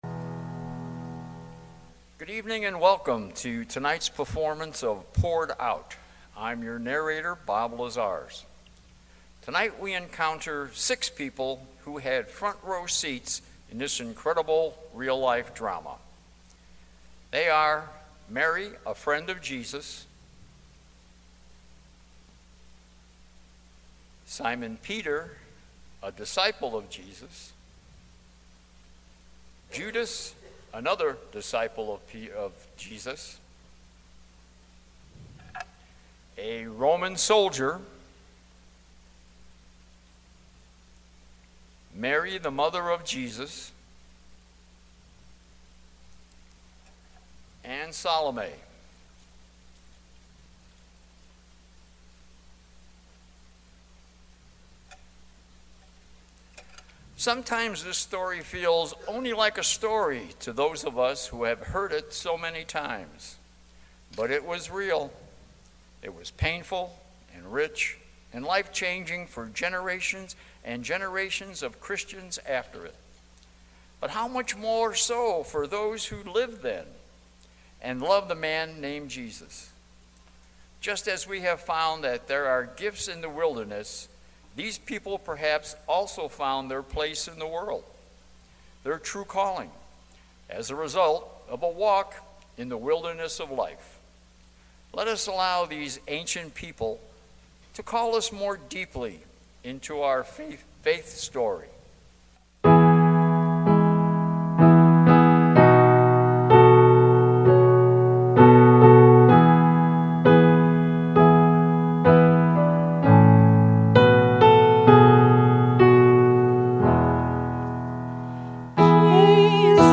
A Maundy Thursday Drama